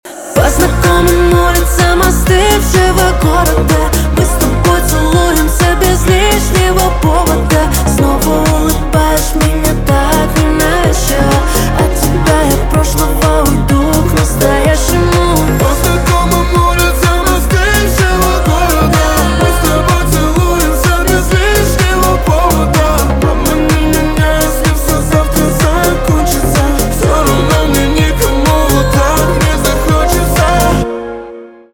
поп
грустные
битовые